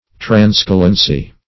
Meaning of transcalency. transcalency synonyms, pronunciation, spelling and more from Free Dictionary.
transcalency.mp3